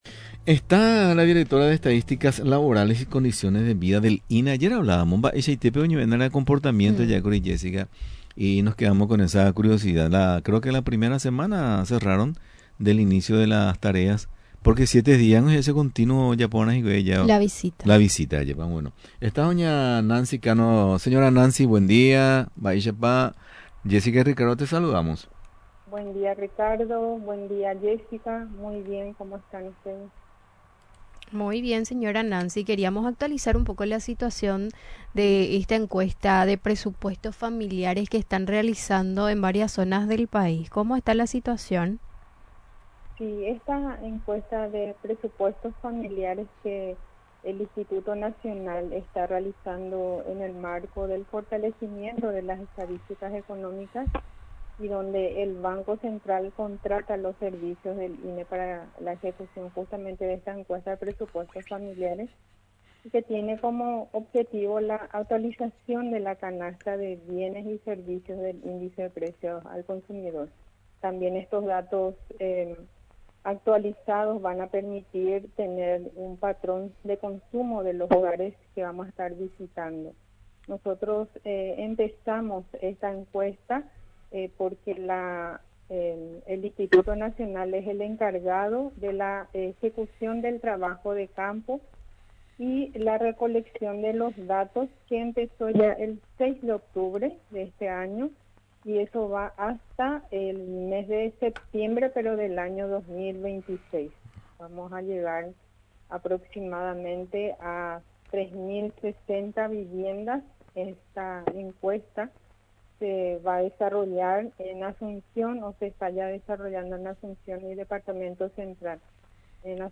en entrevista con Radio Nacional, actualizó los datos sobre la Encuesta de Presupuestos Familiares que está en curso. La encuesta, solicitada por el Banco Central en el marco del fortalecimiento de las estadísticas económicas, tiene como objetivo principal actualizar la canasta de bienes y servicios, utilizada para calcular el Índice de Precios al Consumidor (IPC).